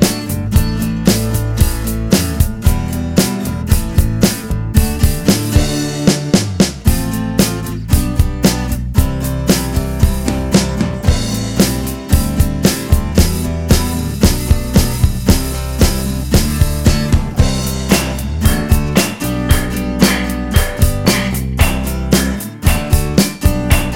no Piano Pop (1980s) 3:44 Buy £1.50